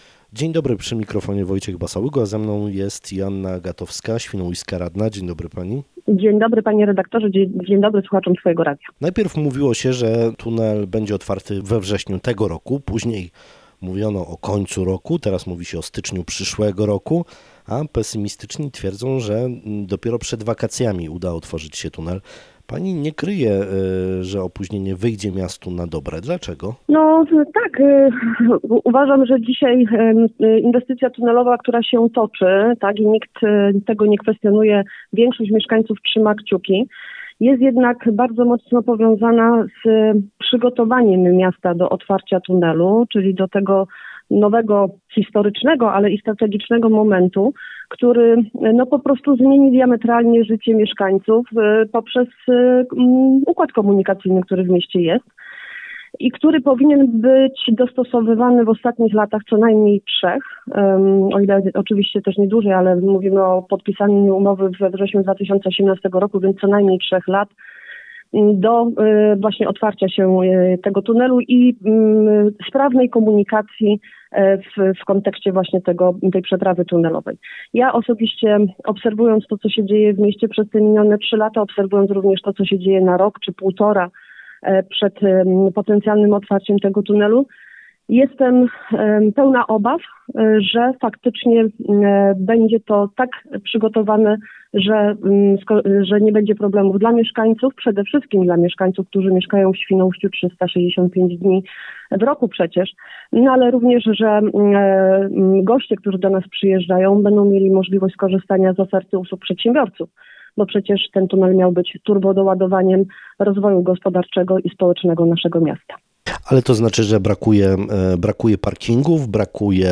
Naszym gościem w Rozmowie Dnia jest Joanna Agatowska, radna Świnoujścia, którą zapytaliśmy między innymi o to, czy miasto jest przygotowane do otwarcia tunelu.